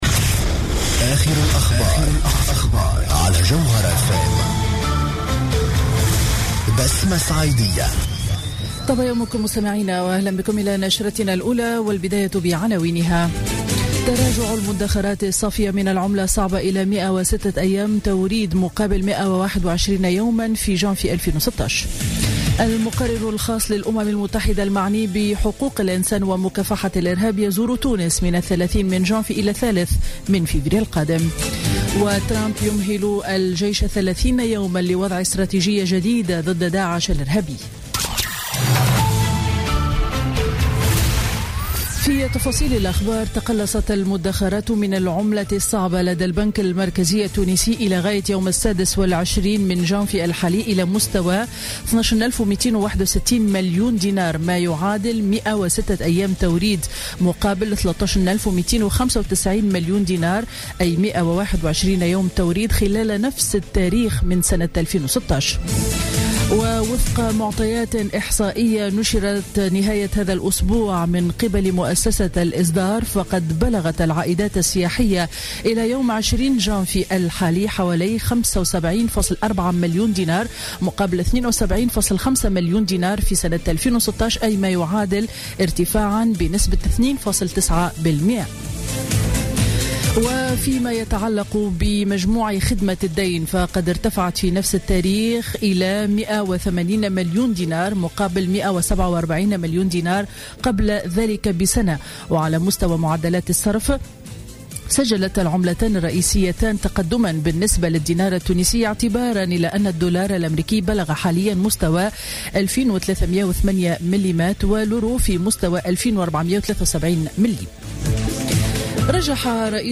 نشرة أخبار السابعة صباحا ليوم الأحد 29 جانفي 2017